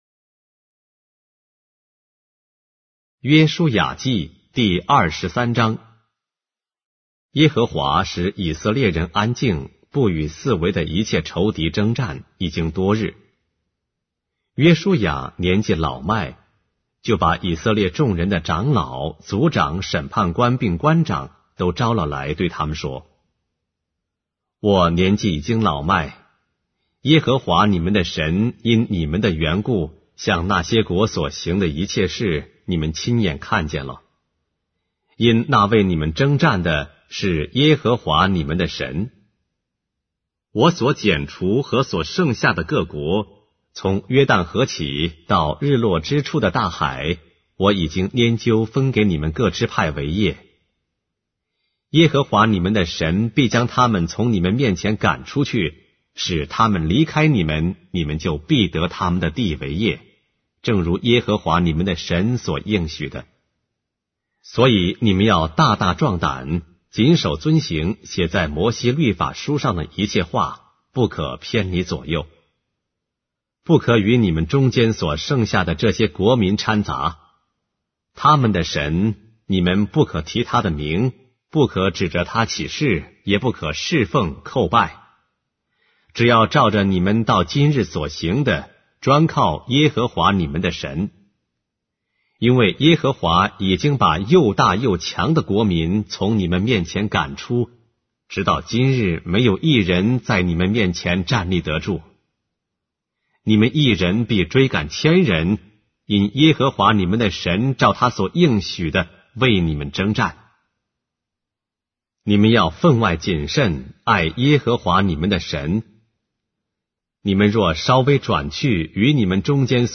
书的圣经 - 音频旁白 - Joshua, chapter 23 of the Holy Bible in Simplified Chinese